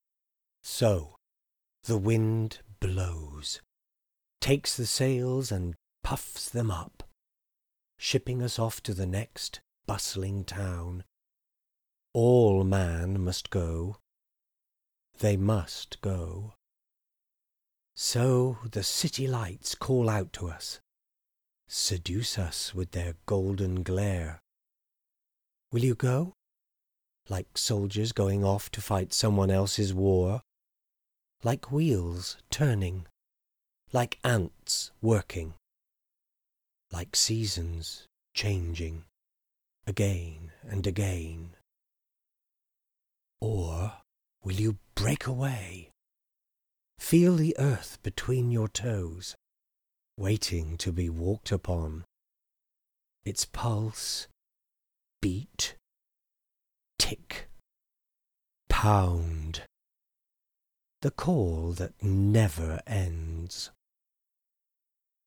General UK & RP
I have my own home studio and can record with a regionally neutral UK accent or if needed a Heightened RP.